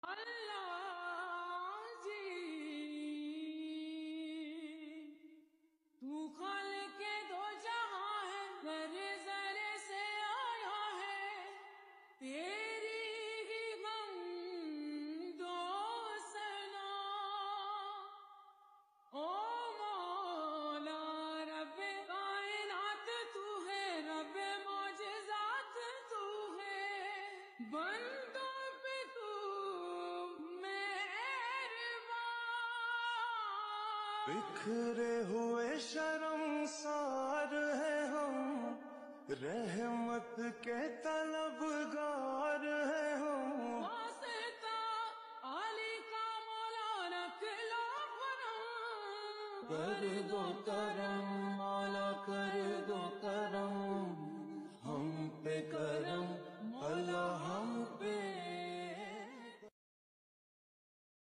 NAAT STATUS